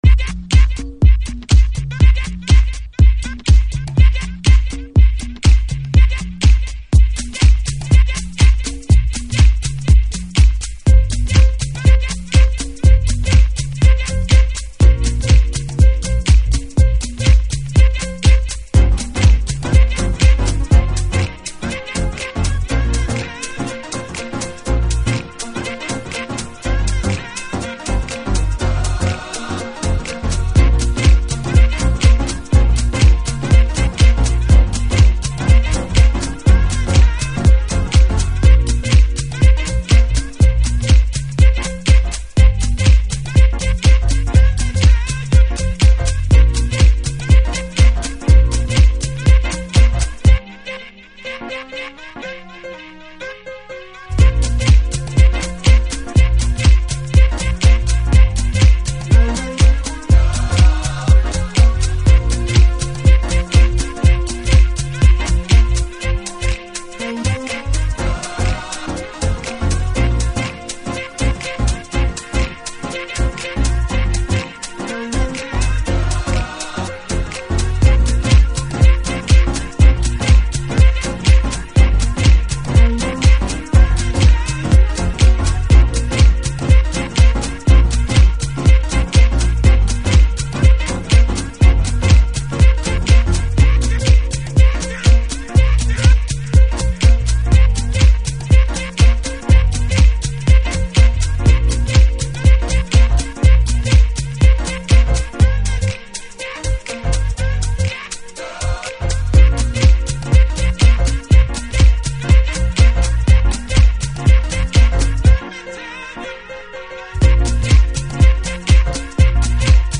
Detroit House / Techno
90'sマナーのディープとガラージの折衷感覚とゼロ年代以降のデトロイトのスモーキーな音像を併せ持ったTRAX。